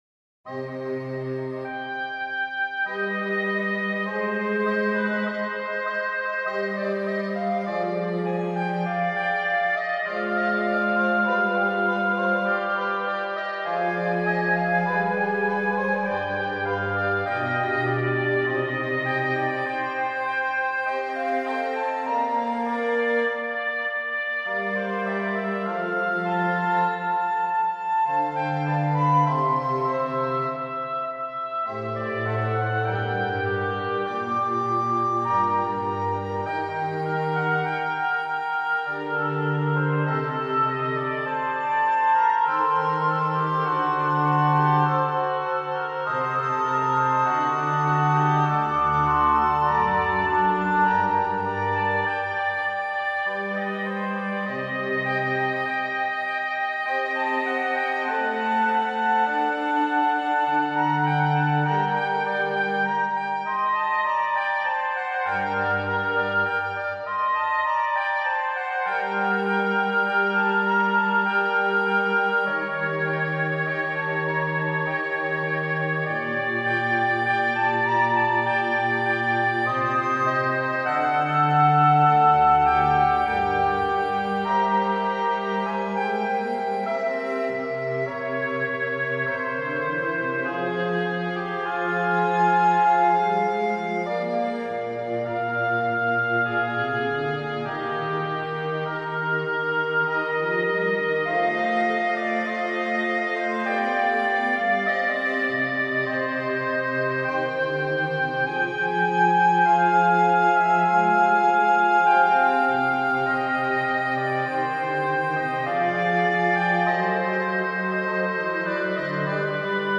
Telemann, Georg Philipp - Trio Sonata, TWV 42:C2 Free Sheet music for Mixed Trio
Recorder Oboe Bass
Style: Classical